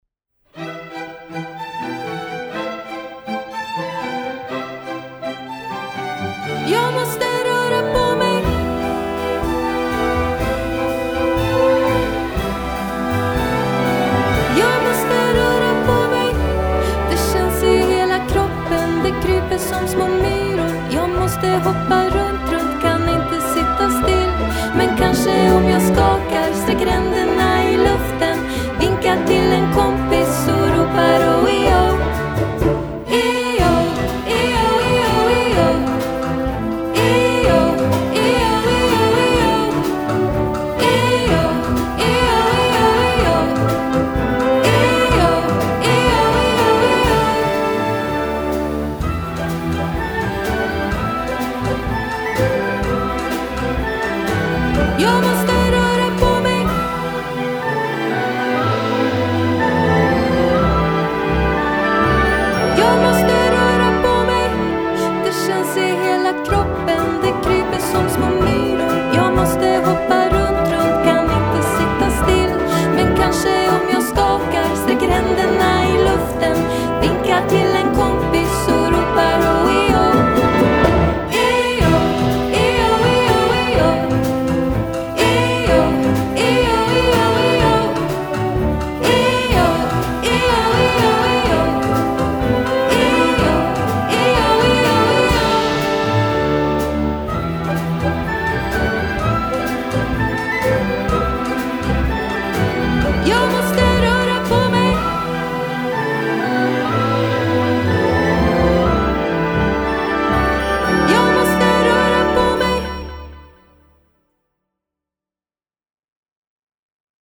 En lekfull konsert